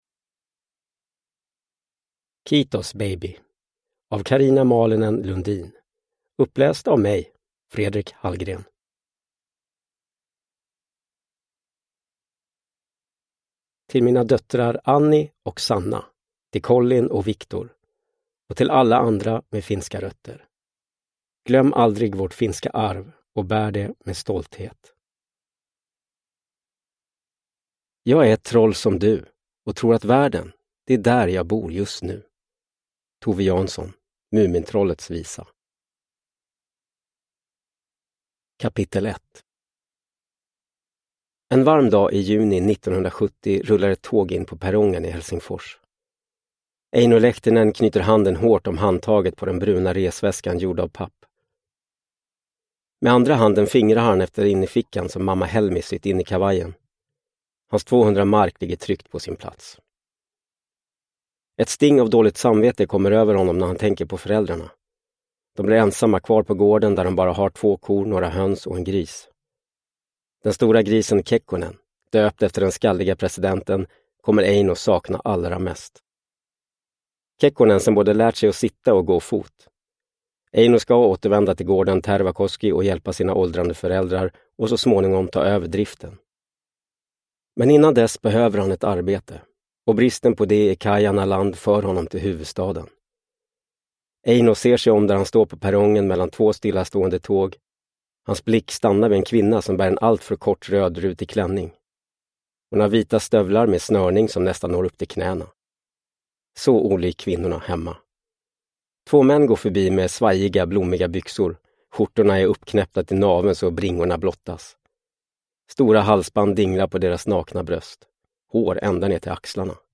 Kiitos baby! – Ljudbok – Laddas ner